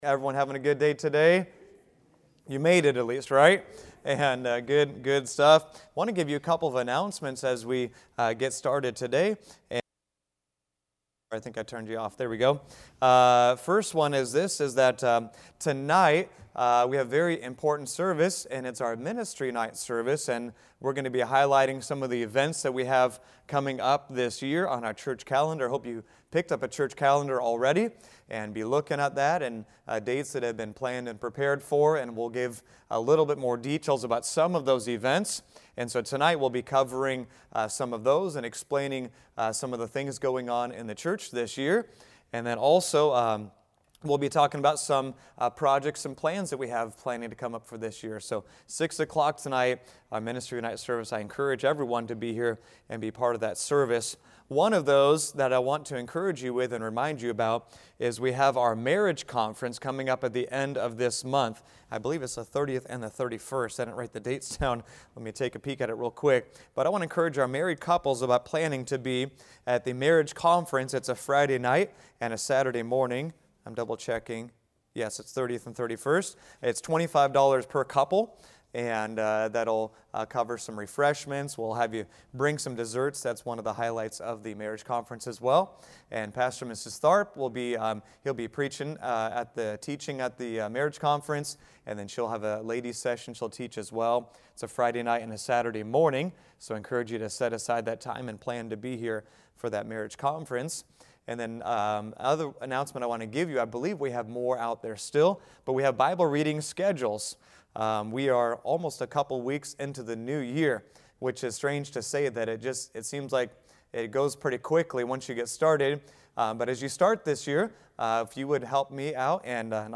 Prayer | Sunday School – Shasta Baptist Church